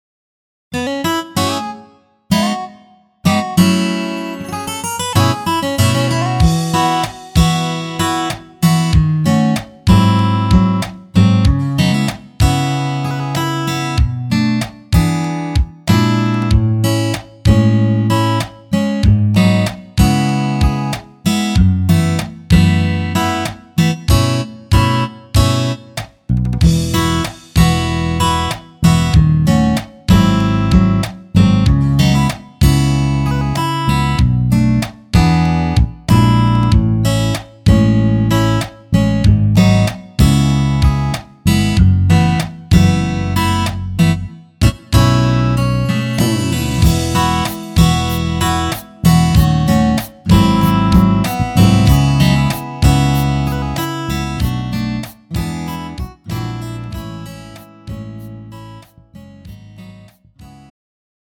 음정 -1키 4:04
장르 가요 구분 Pro MR
Pro MR은 공연, 축가, 전문 커버 등에 적합한 고음질 반주입니다.